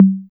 10 CONGA.wav